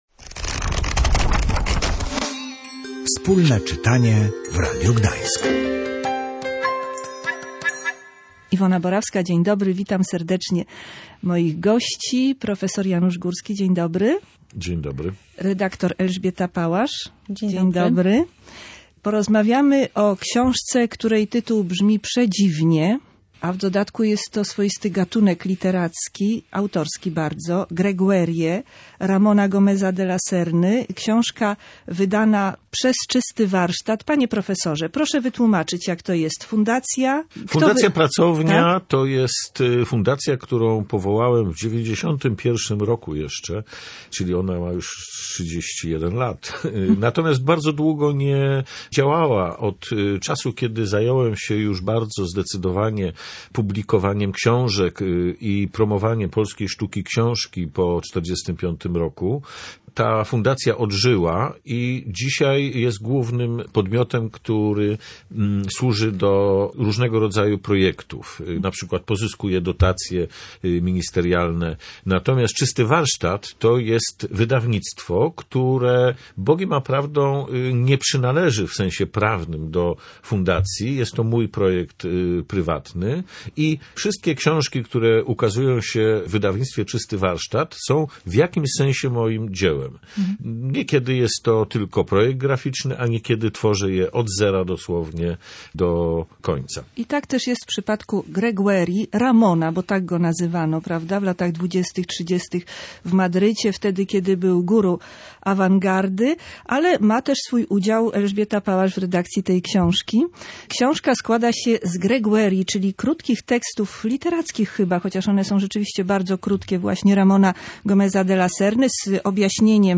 Ramon Gomez de la Serna, „Greguerie”. Rozmowa